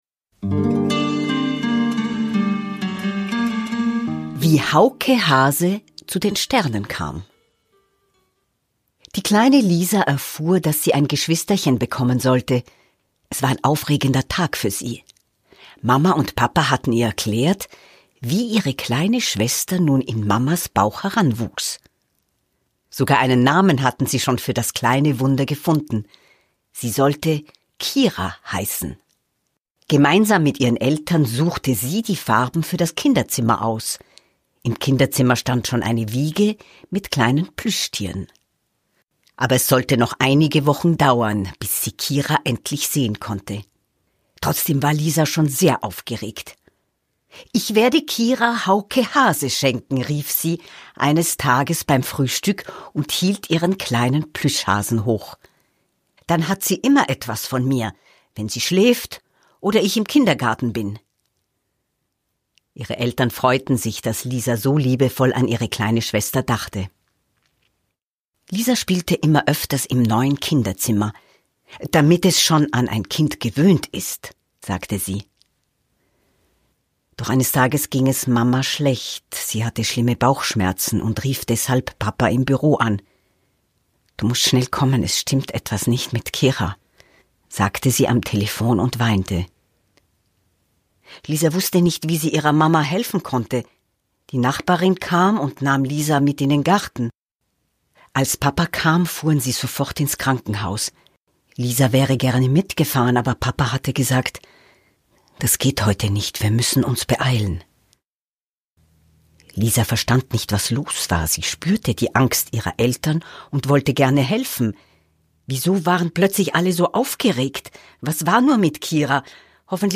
In dieser bewegenden Hörgeschichte begleiten wir Lisa, die voller Vorfreude auf die Geburt ihrer kleinen Schwester Kira wartet – bis ein unerwarteter Abschied ihre Welt verändert.
Eine liebevoll erzählte Geschichte für Eltern, denen die Worte noch fehlen oder die, die mit ihren Kindern über Verlust und Liebe sprechen möchten.